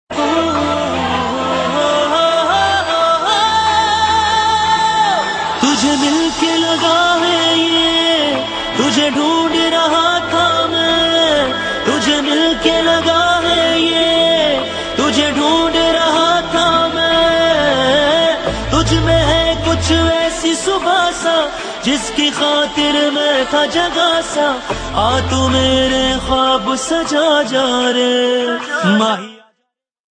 Bollywood & Indian